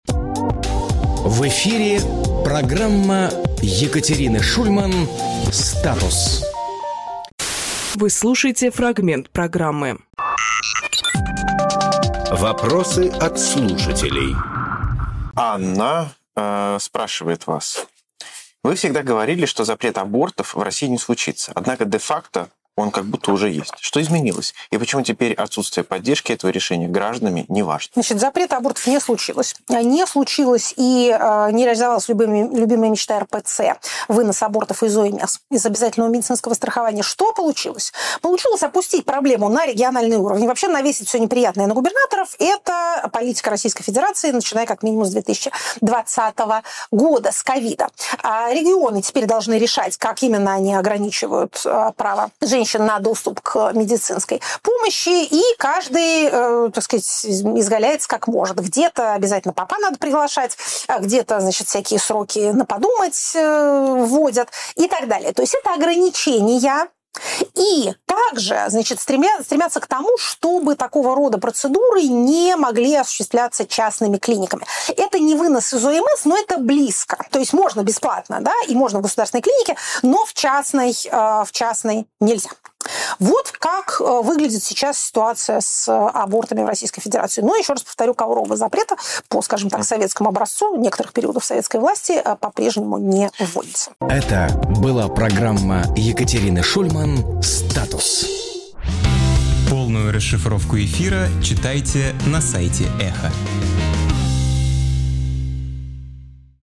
Екатерина Шульманполитолог
Фрагмент эфира от 03.02.26